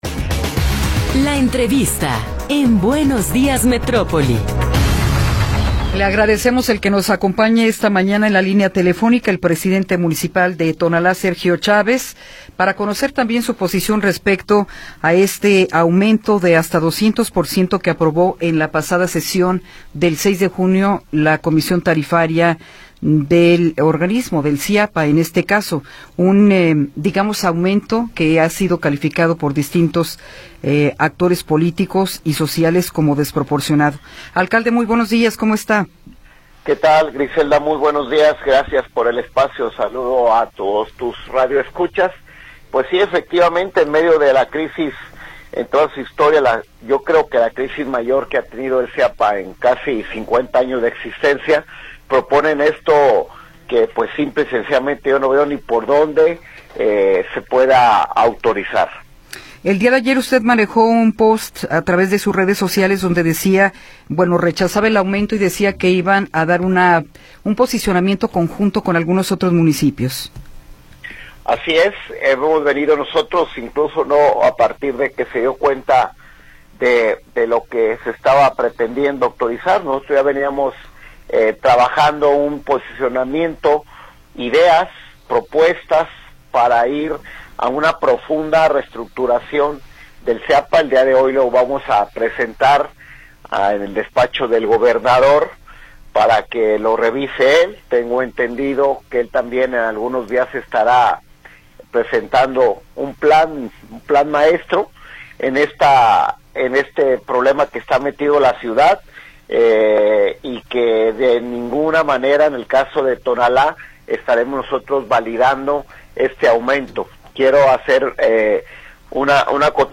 Entrevista con Sergio Chávez